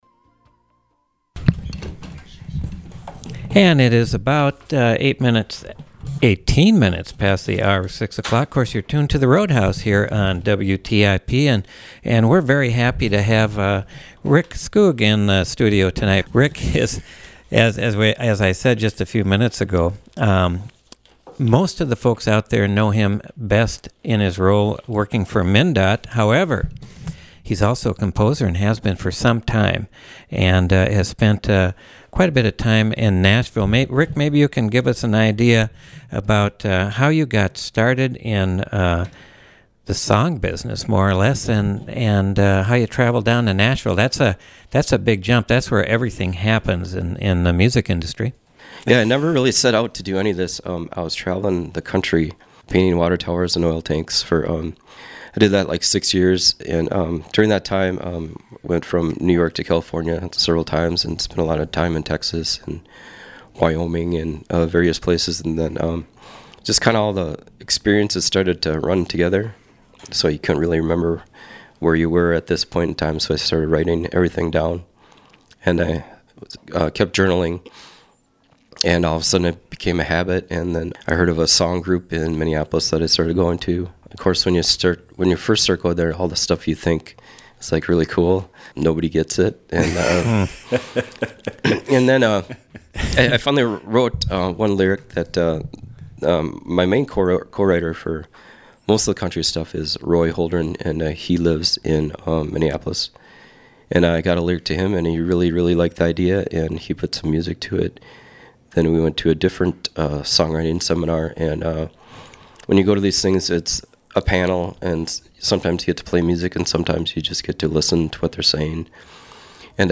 You'll hear two songs he co-wrote in this interview. Program: The Roadhouse